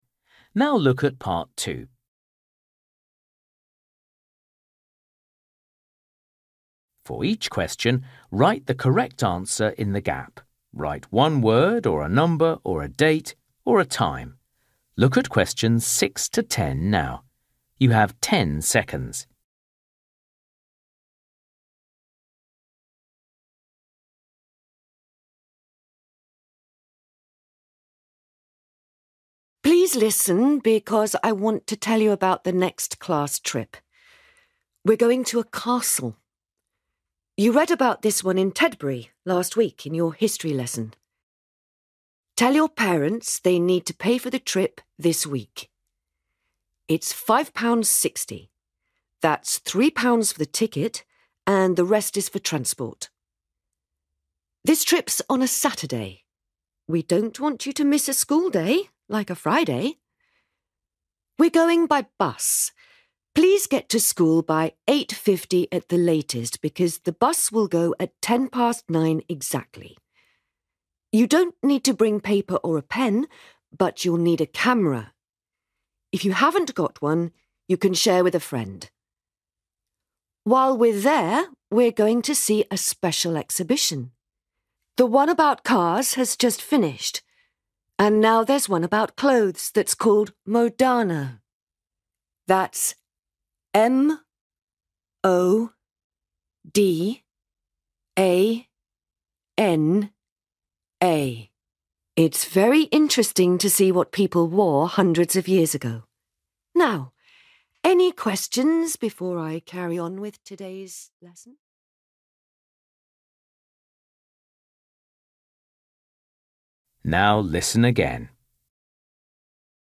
You will hear a teacher telling some students about a class trip.